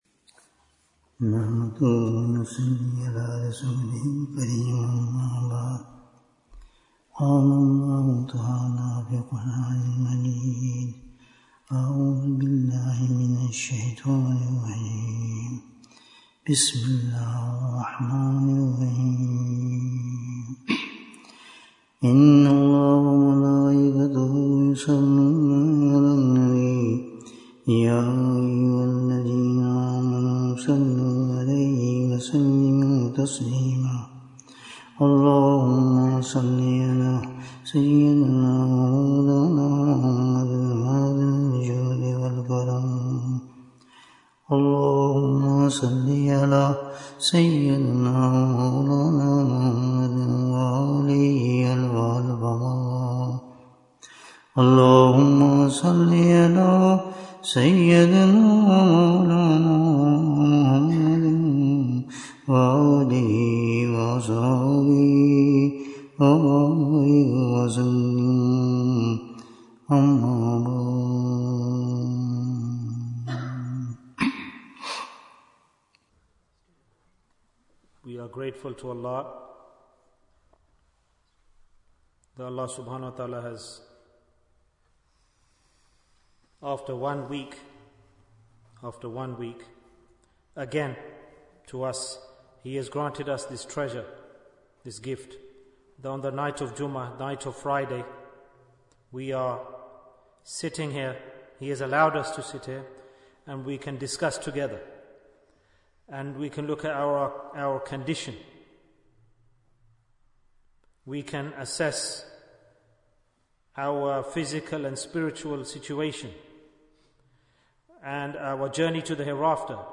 Which is the Straight Path? Bayan, 79 minutes10th October, 2024